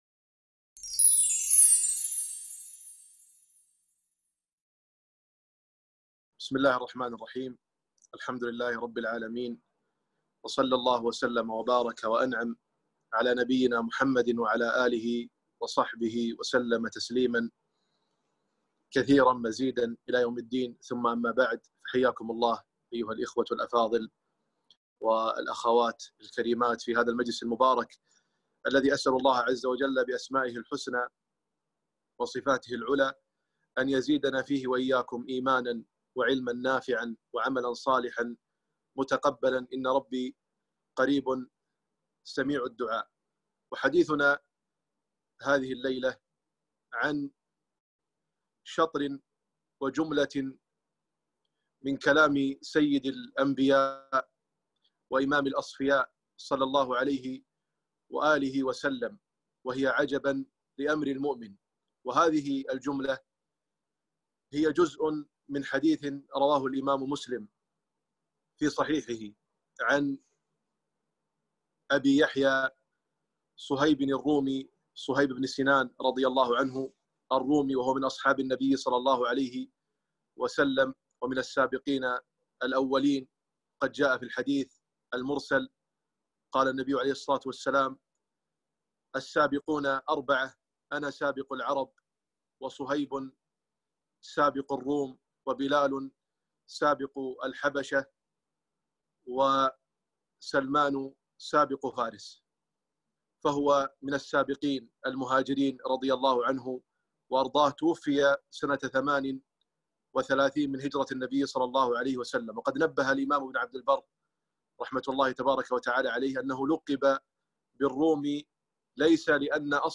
محاضرة - عجباً لأمر المؤمن